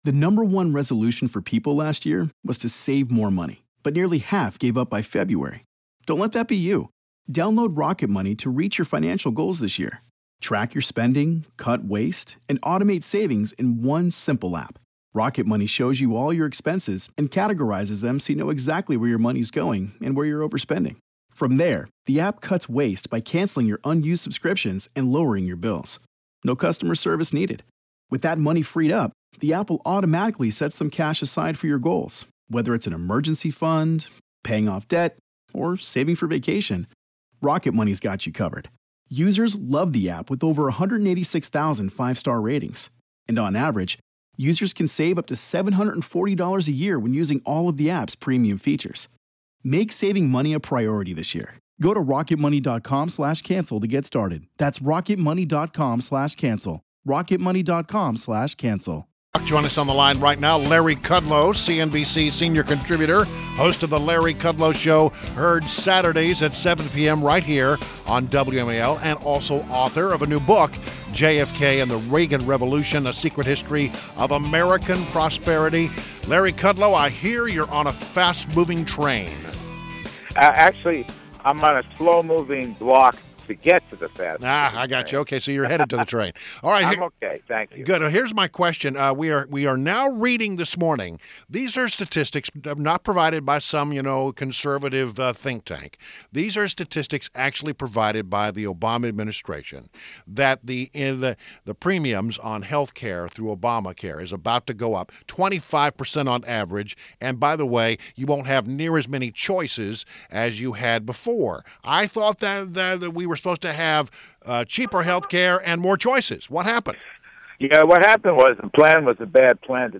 INTERVIEW — LARRY KUDLOW – CNBC Senior Contributor and host of The Larry Kudlow Show on WMAL Saturdays at 7 pm